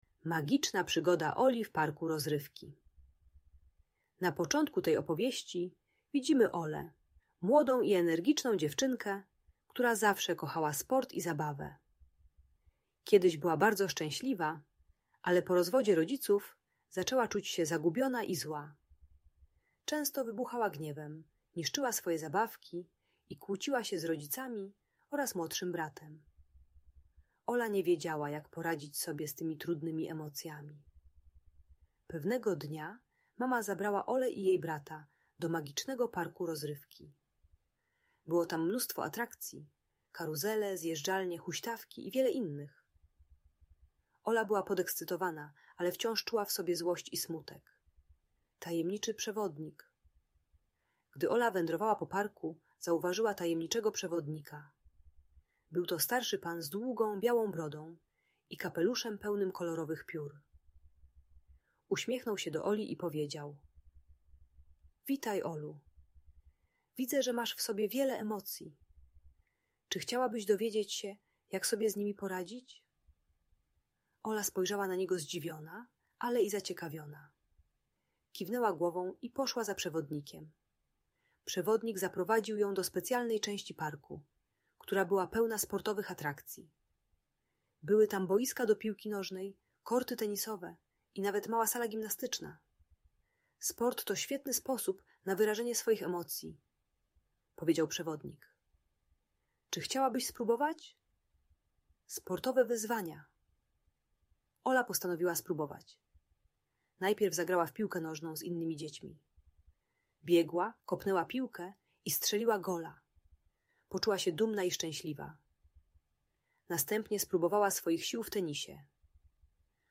Audiobook dla dzieci 5-8 lat o radzeniu sobie z agresją po rozstaniu rodziców. Ola uczy się wyrażać trudne emocje przez sport, sztukę, muzykę i medytację zamiast niszczyć zabawki i kłócić się z rodziną.